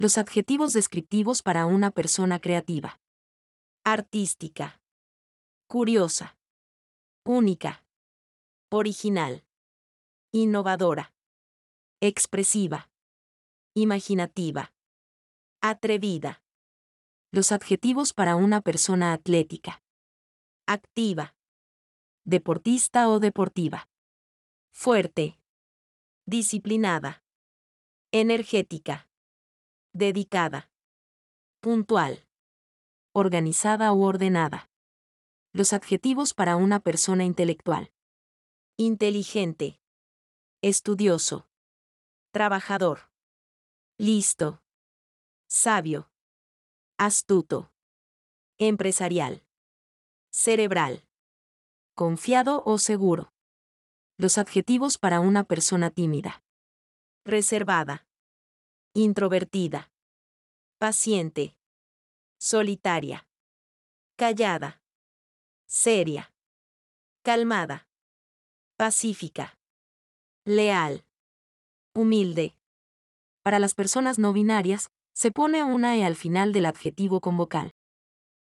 Play the audio to hear each adjective pronounced.